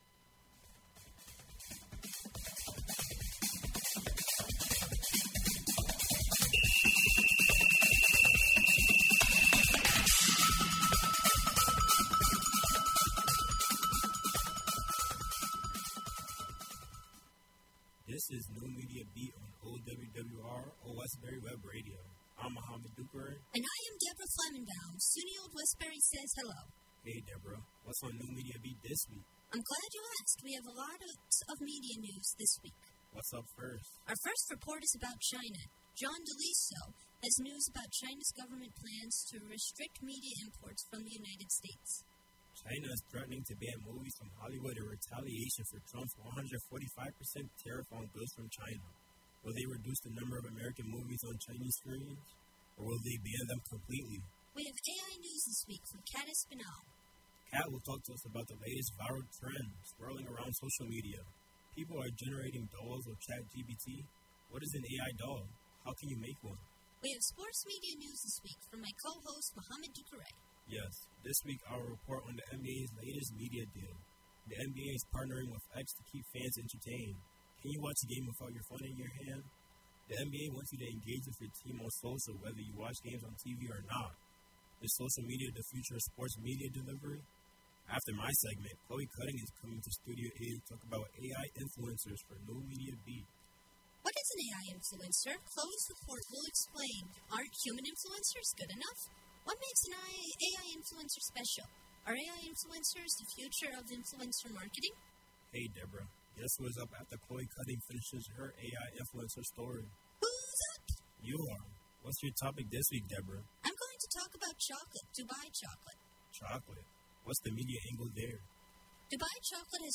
The NMB Podcast streams live on Old Westbury Web Radio Thursdays from 12:00-1:00 PM EST. Can’t listen live?